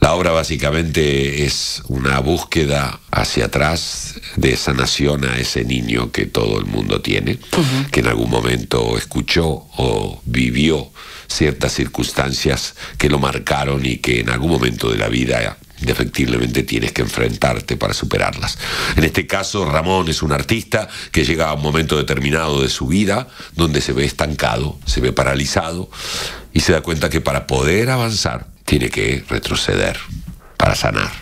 “És un camí cap a la curació”, ha explicat en una entrevista al magazine a l’FM i més